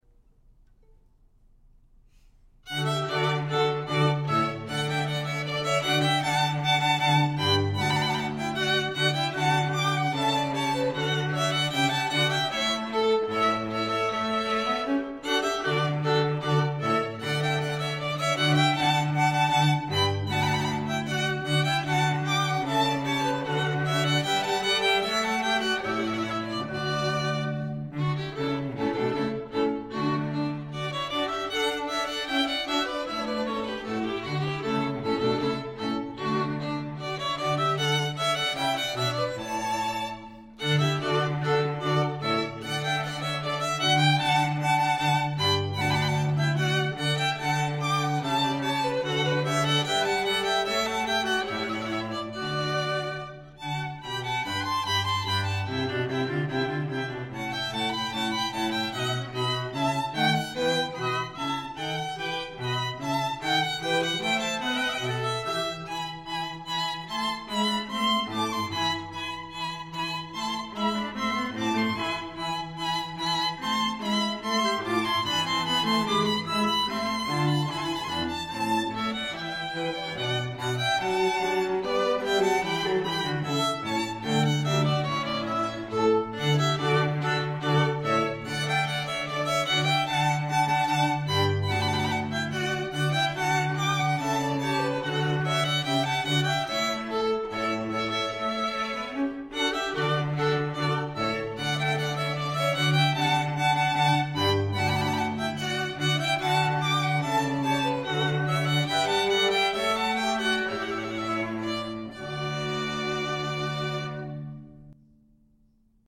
Elysium String Quartet – performing throughout the Lehigh Valley, Delaware Valley, and Philadelphia, PA